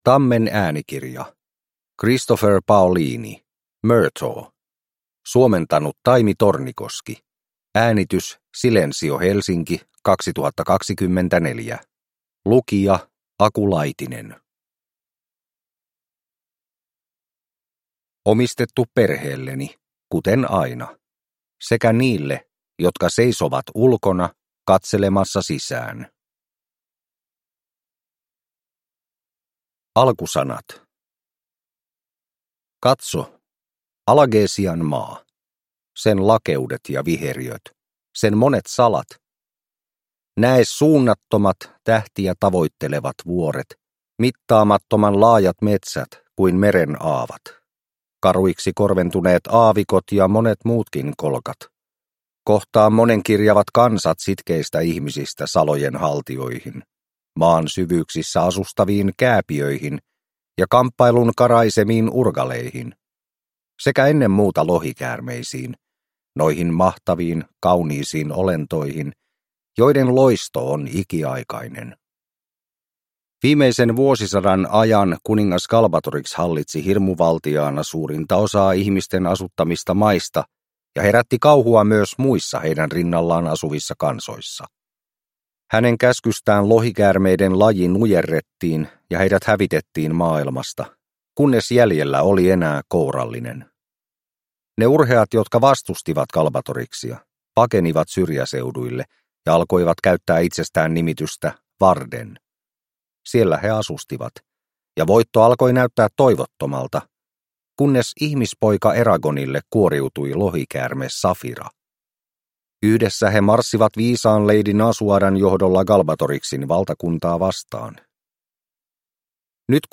Murtagh – Ljudbok